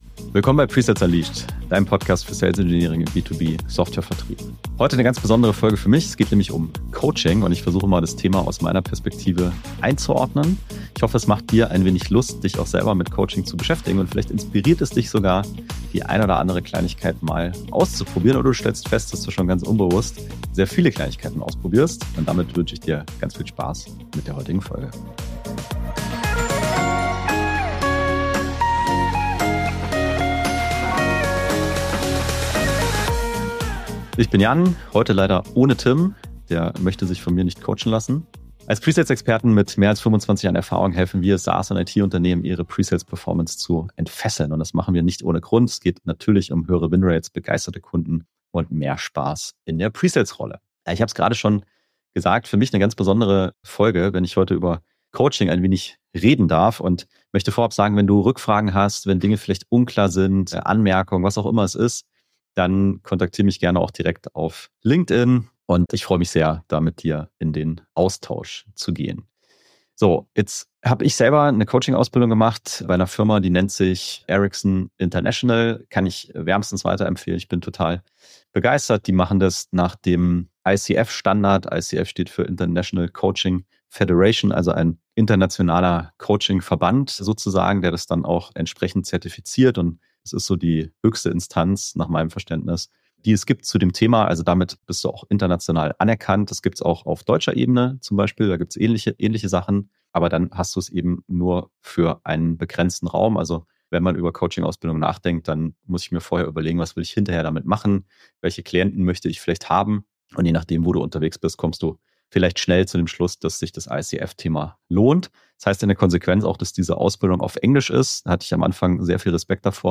1 Kostüme, Keynotes & Killer-Workshops: Artist Circus 2025 Inside (209) 27:59 Play Pause 14d ago 27:59 Play Pause Später Spielen Später Spielen Listen Gefällt mir Geliked 27:59 Der Arrtist Circus 2025 steht an und wir sind wieder als Co-Hosts des Pre-Sales-Tracks dabei! Doch diesmal läuft es anders: Eine KI stellt uns die Fragen. Warum geben wir Pre-Sales so viel Sichtbarkeit?